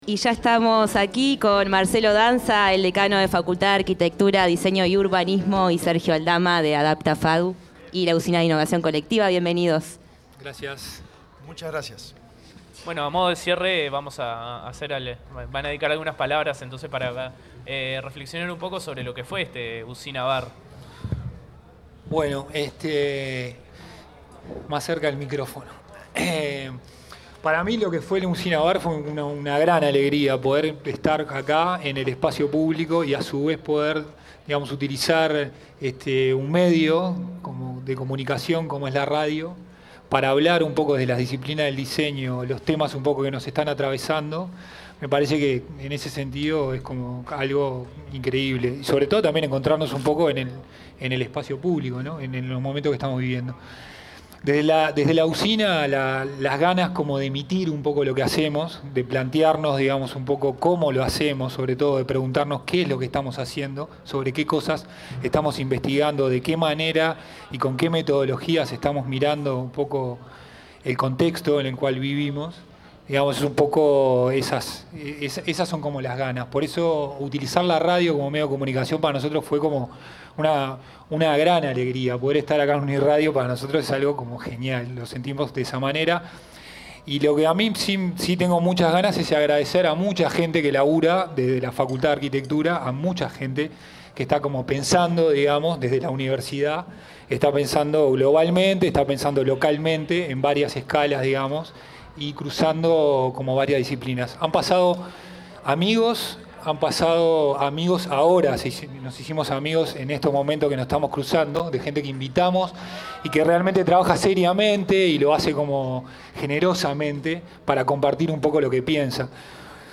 En un formato híbrido entre un programa de radio en vivo y un bar abierto en Plaza Las Pioneras de Montevideo, destacados invitados locales e internacionales debatieron y expusieron colectivamente sobre temáticas implicadas en la adaptación al cambio y la variabilidad climática, desde la mirada del diseño, la arquitectura y el p